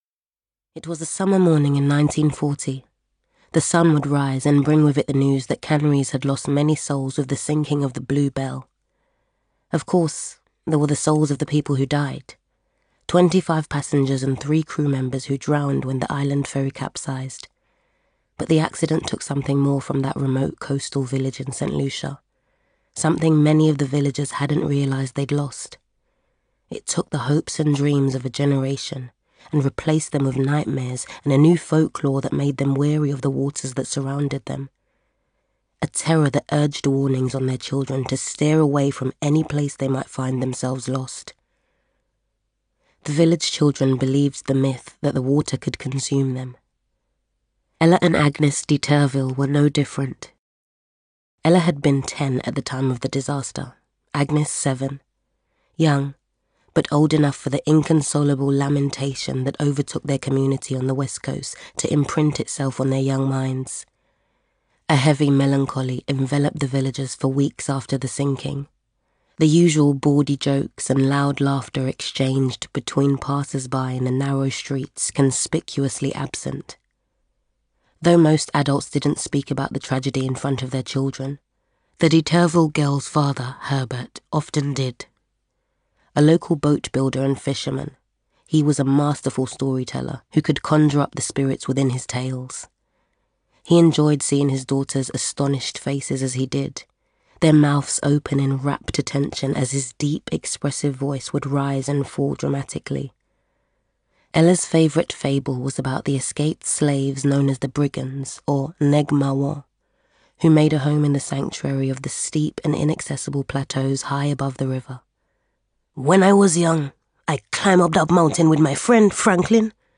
Caribbean
London
Female
Conversational
Cool
Husky
ISLAND SONG AUDIOBOOK